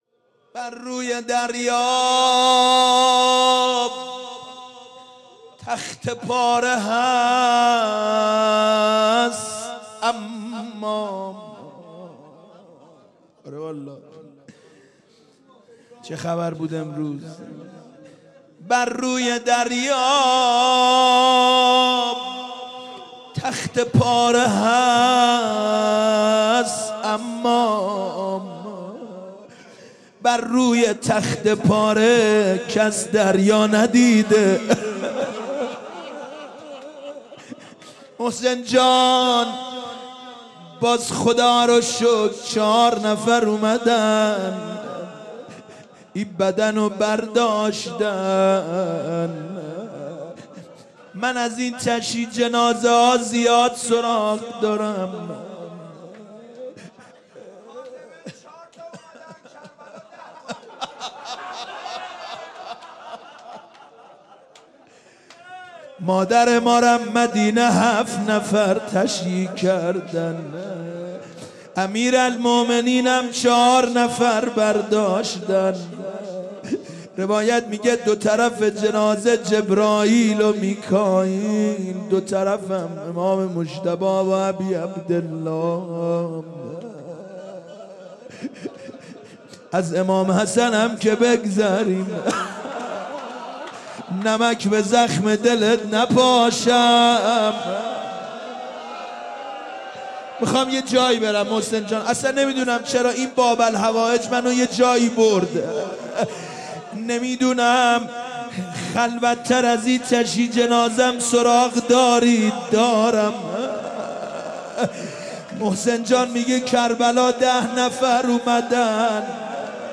شب شهادت امام موسی کاظم (ع)/بیت الرضا(ع)